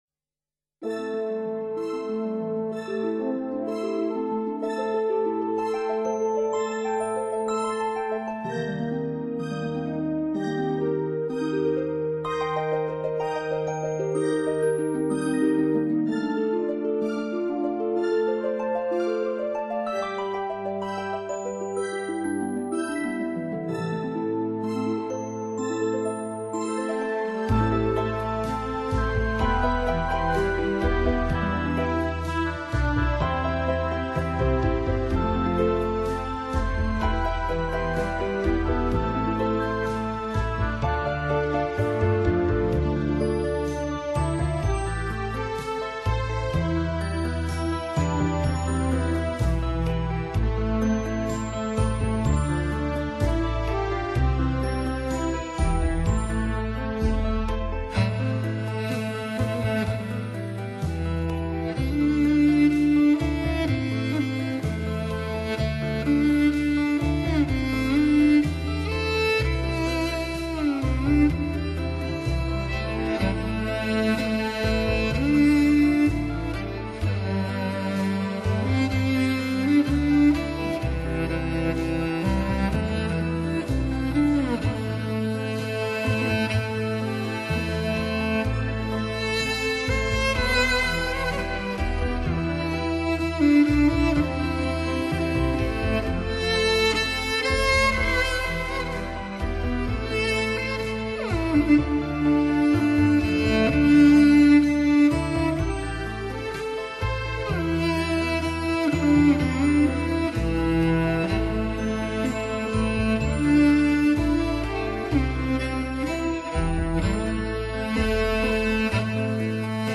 马头琴演奏：湖韵